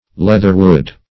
Leatherwood \Leath"er*wood`\, n. (Bot.)